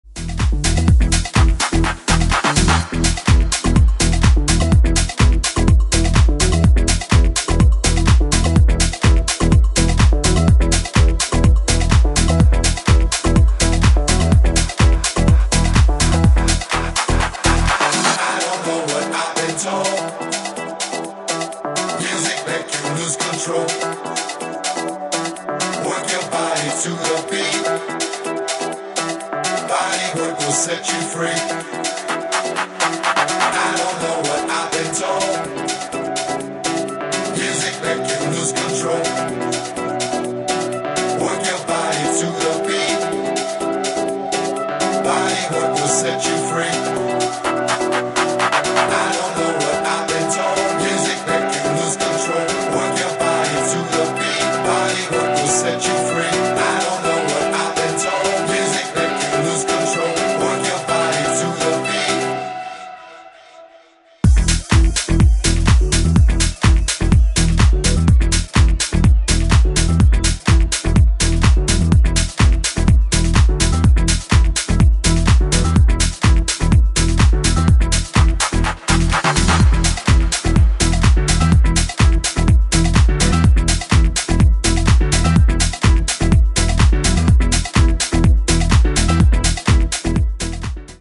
アップ・ハウスでフロア重視の全4バージョン
ジャンル(スタイル) HOUSE / DISCO HOUSE